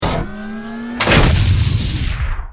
battlesuit_handcannon.wav